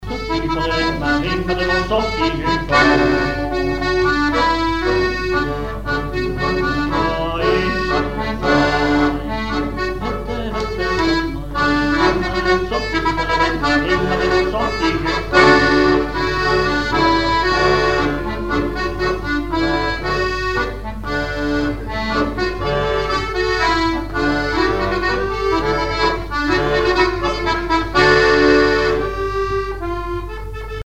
Fonction d'après l'informateur gestuel : à marcher
Genre laisse
chansons et instrumentaux
Catégorie Pièce musicale inédite